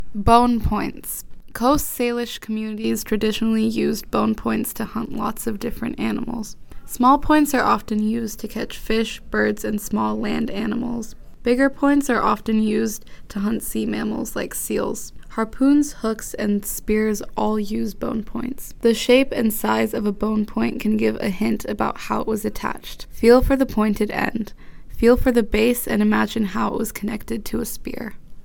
The audio guide to the kits is provided below.